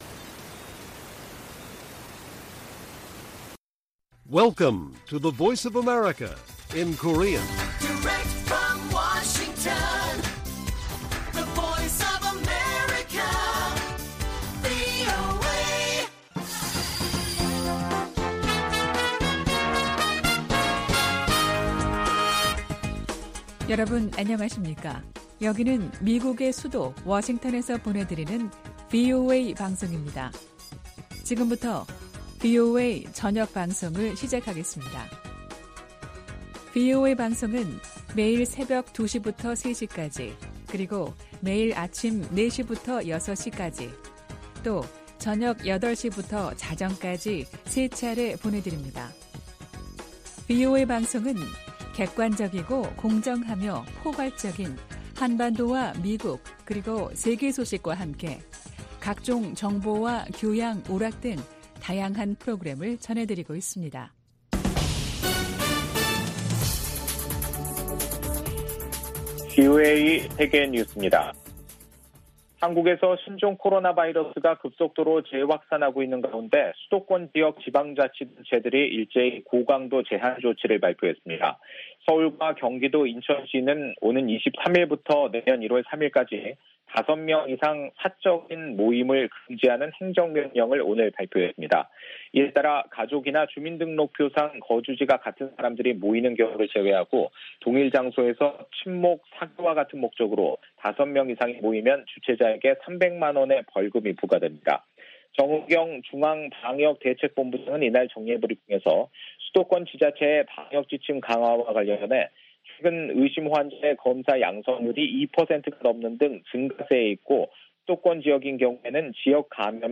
VOA 한국어 간판 뉴스 프로그램 '뉴스 투데이' 1부 방송입니다.